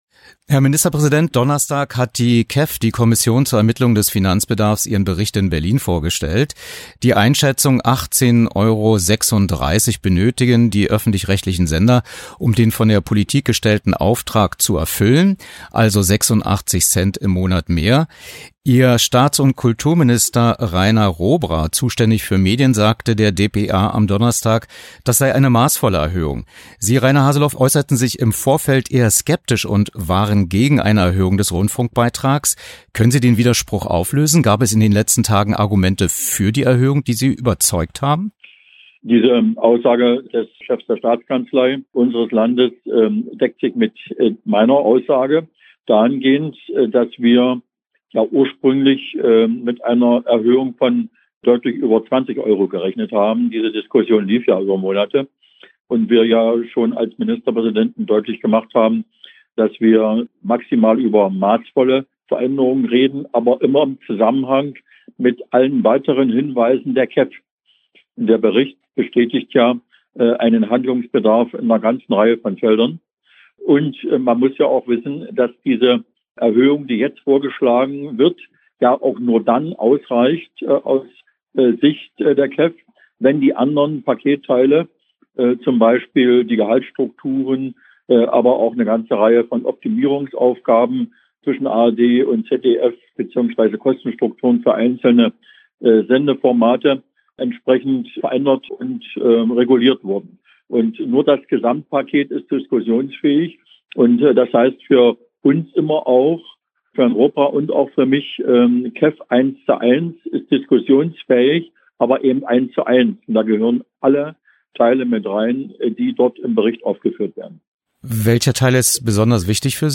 Was: Telefoninterview zum 22. KEF-Bericht Wer: Dr. Reiner Haseloff, Ministerpräsident Sachsen-Anhalt Wann: rec.: 21.02.2020, 15:15 Uhr für das Medienmagazin auf radioeins am 22.02.2020 und im rbb-Inforadio am 23.02.2020